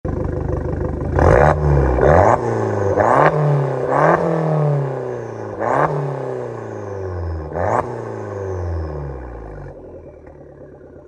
Der G-TECH Klappen-Mittelschalldämpfer reguliert die Abgasmenge und den Klang über eine Stauklappe für ein sportliches und dennoch alltagstaugliches Sounderlebnis.
Audio: GT550-ST mit offener Klappe
G_TECH_GT550-ST_Klappe-offen-1.mp3